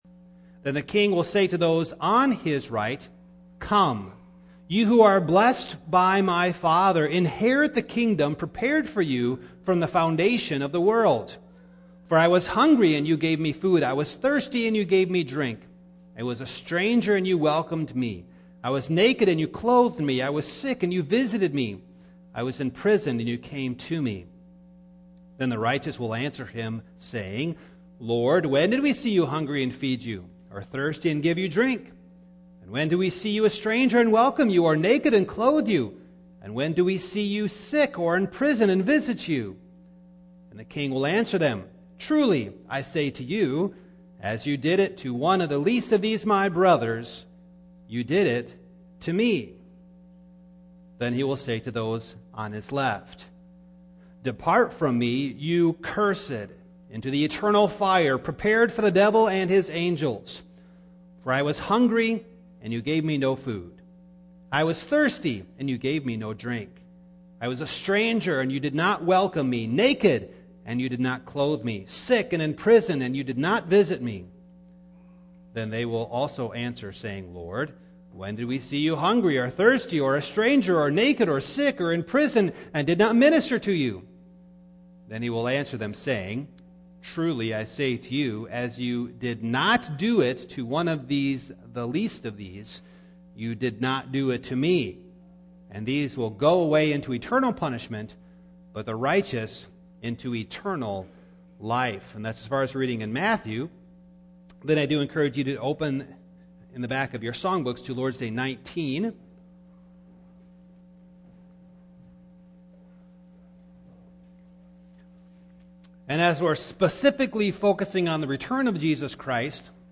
2022-23 Passage: Matthew 25:31-46 Service Type: Morning Download Files Notes « He Ascended Into Glory By Sin